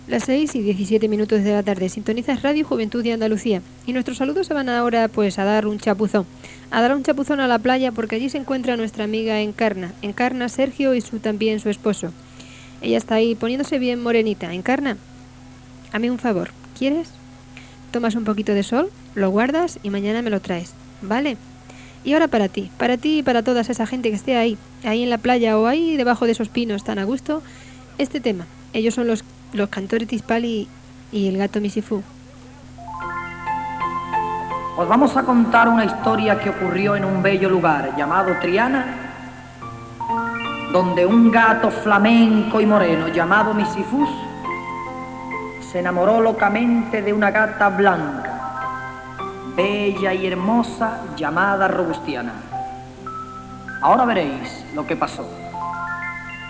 Hora, identificació i tema dedicat als qui estan a la platja.
Musical
FM